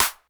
snr_23.wav